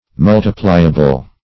Search Result for " multipliable" : The Collaborative International Dictionary of English v.0.48: Multipliable \Mul"ti*pli`a*ble\, a. [Cf. F. multipliable.] Capable of being multiplied.